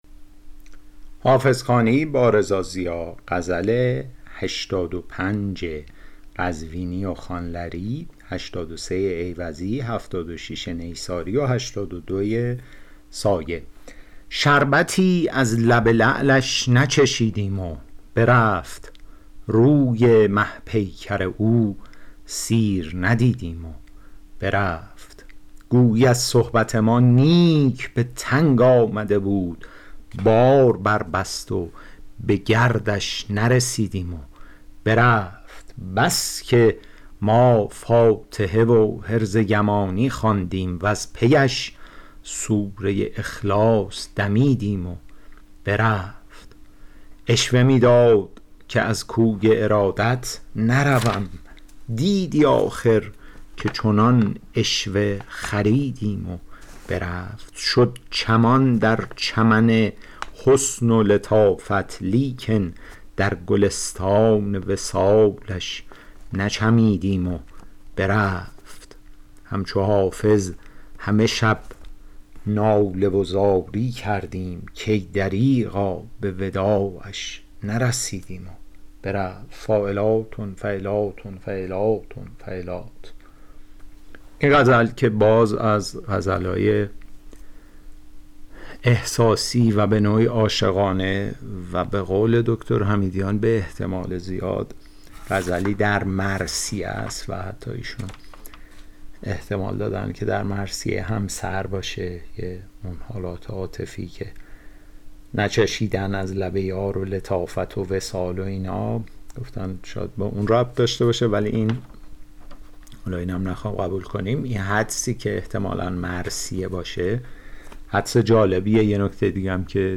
شرح صوتی غزل شمارهٔ ۸۵